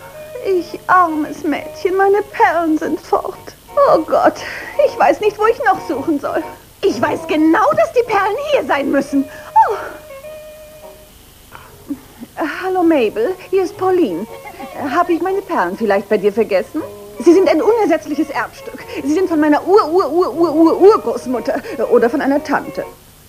- Radiosprecher
- Männerstimme
- Pauline, die "Lady"         -